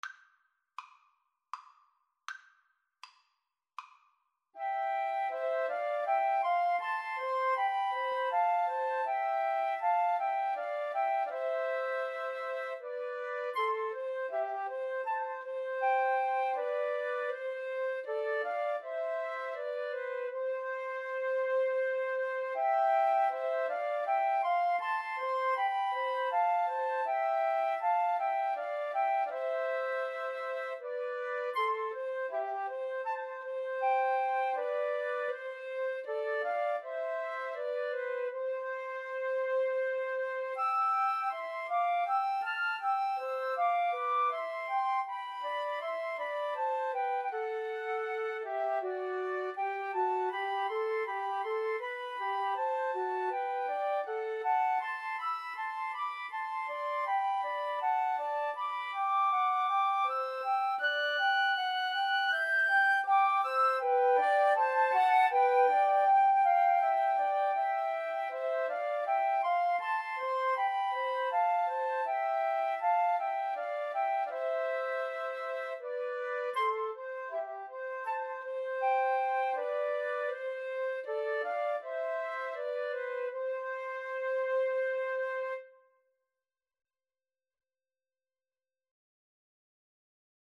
Free Sheet music for Flute Trio
C major (Sounding Pitch) (View more C major Music for Flute Trio )
Andante